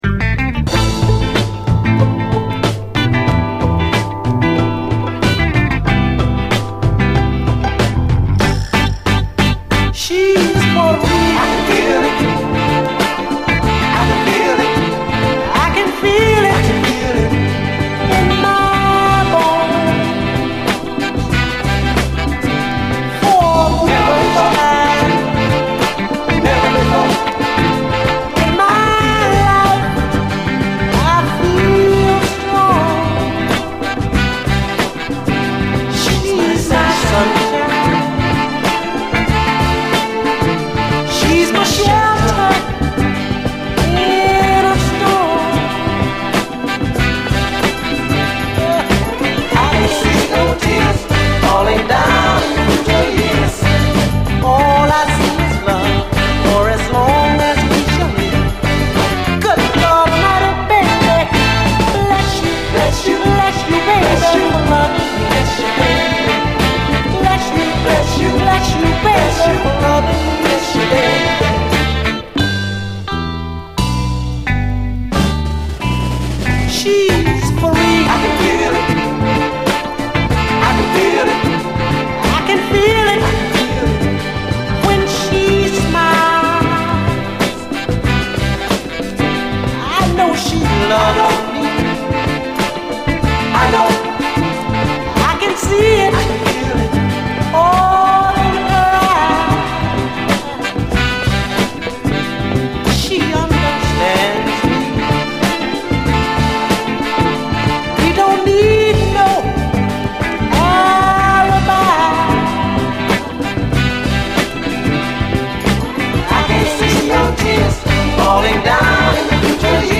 北欧アイスランド産のレア・ブルーアイド・ソウル〜S.S.W.盤！
曲により時折見せる気品あるグルーヴィーなアレンジは、ARTHUR VELOCAIを思わせ、レアグルーヴ・フリークも必聴！
メロウ・ボッサ